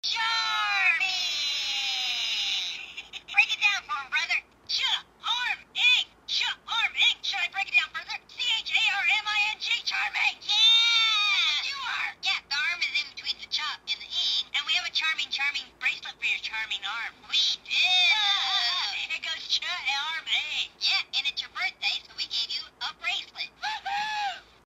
Card sound